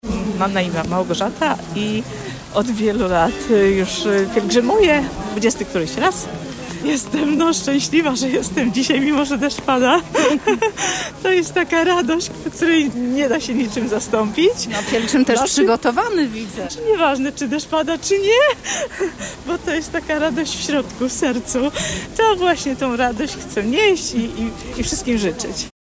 wieloletnia pątniczka.